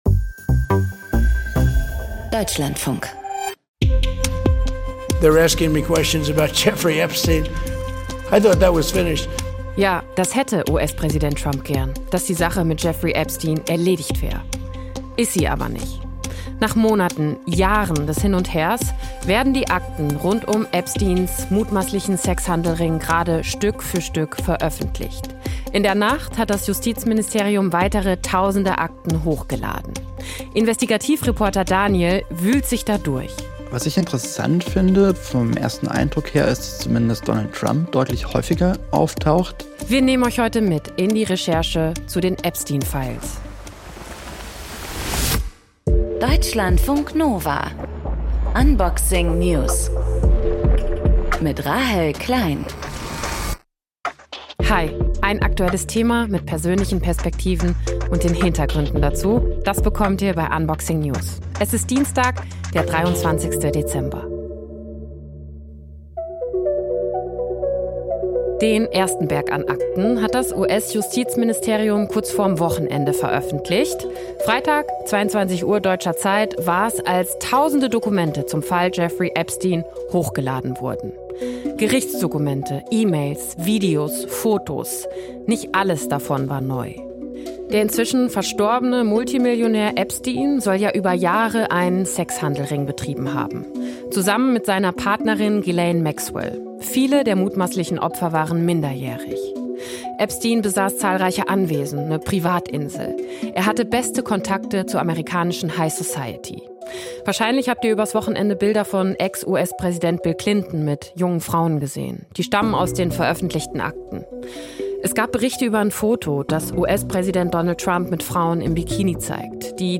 Moderation